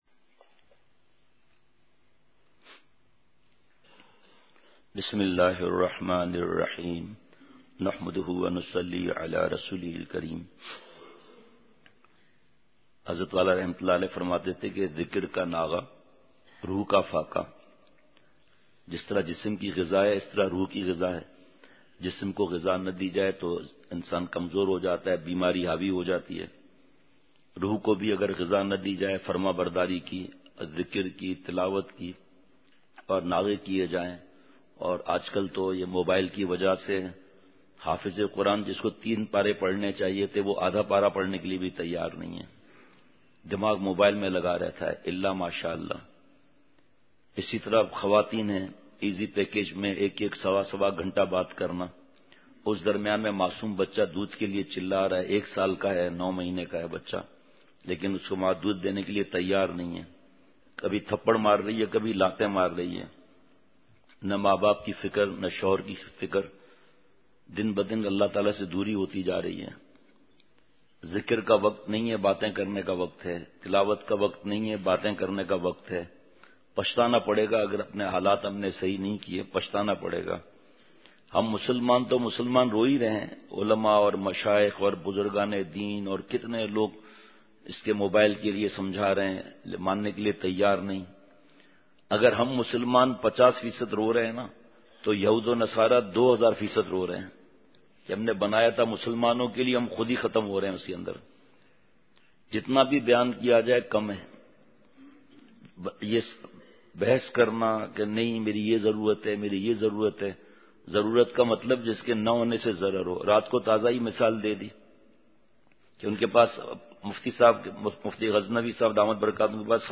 مجلس ۱۵فروری ۲۰۱۹ء بعد فجر : مشائخ کے مجاہدات پر اللہ تعالیٰ کی عطا !